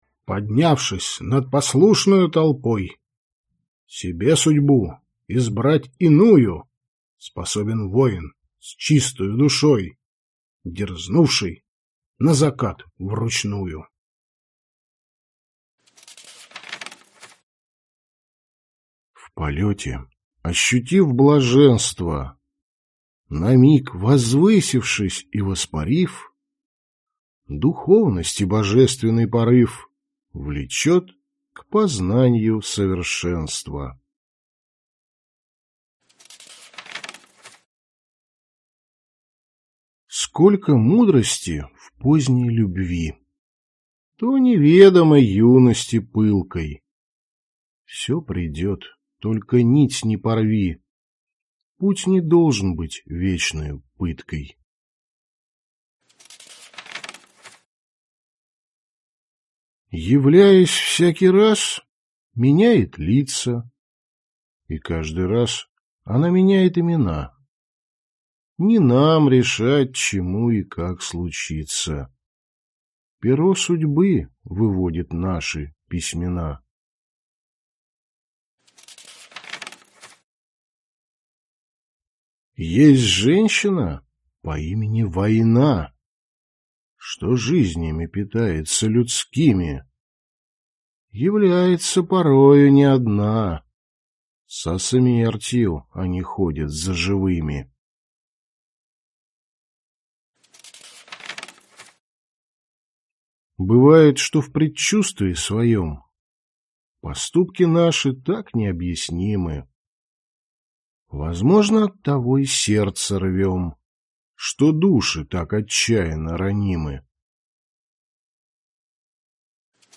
Аудиокнига Закат вручную (сборник) | Библиотека аудиокниг